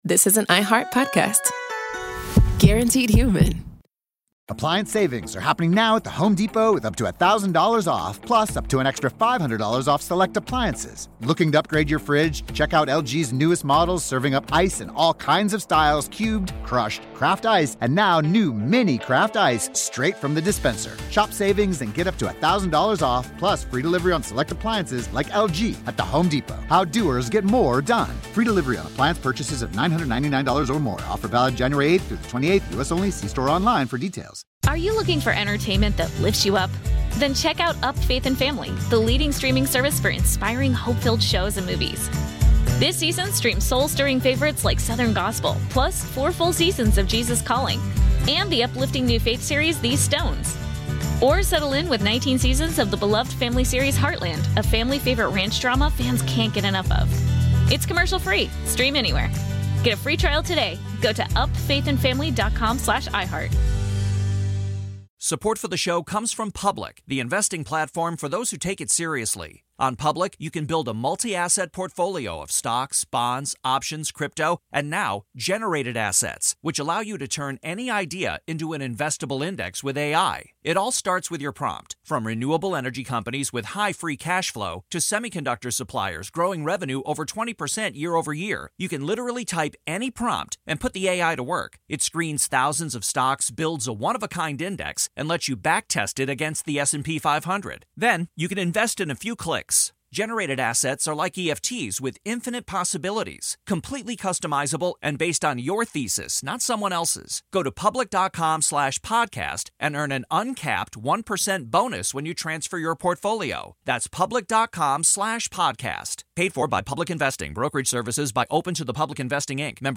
A Son’s Eulogy for His Biker Father